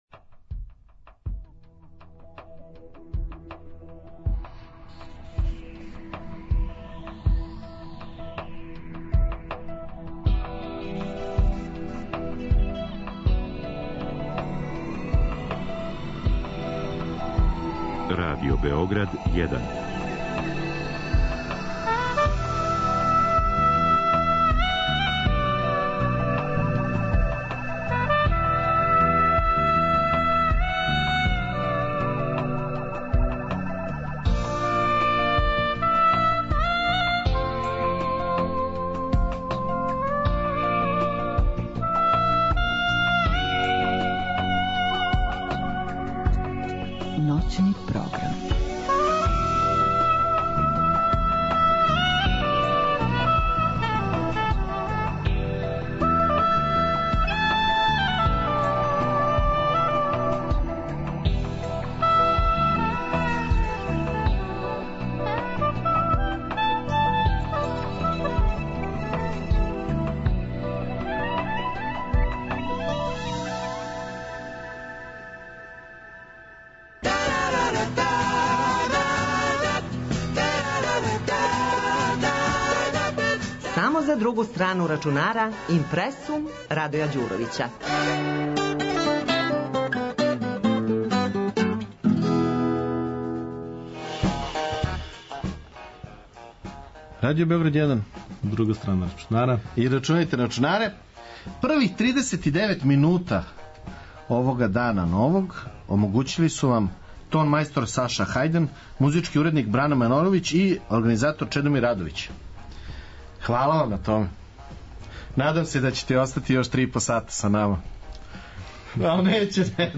NAPOMENA: Ukoliko vas mrzi da čitate ovaj tekst, možete ga preslušati ovde:
28.Juna 2014.godine ovo je tema IMPRESSUM-a, stalne rubrike radio emisije "Druga strana računara" @ Radio BGD 1, od ponoći do jutra!